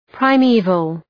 Shkrimi fonetik {praı’mi:vəl}